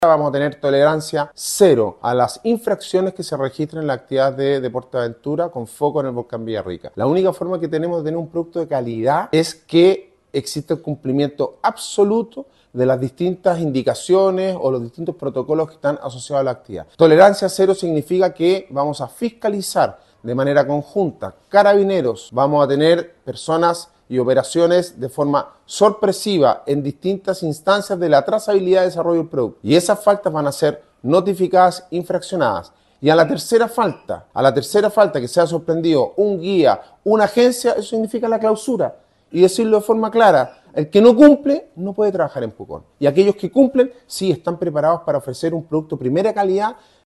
Alcalde-Sebastian-Alvarez-indica-medidas-restrictivas-a-agencias-que-NO-cumplan.mp3